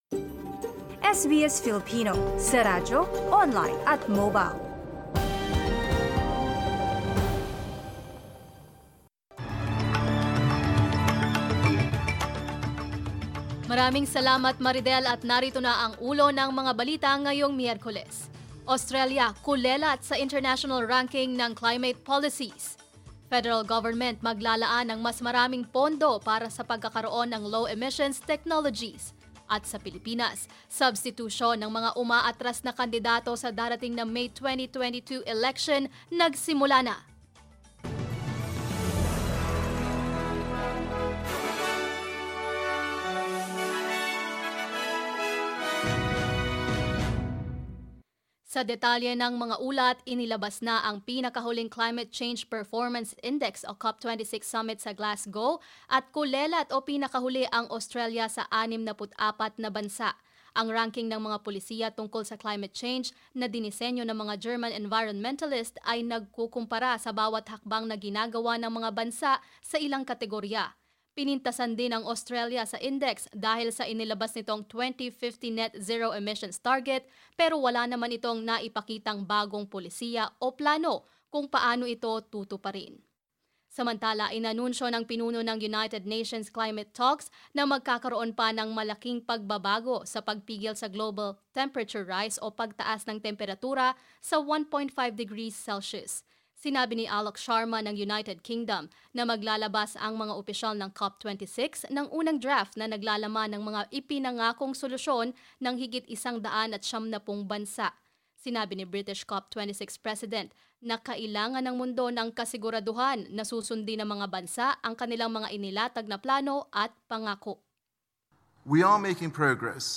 SBS News in Filipino, Wednesday 10 November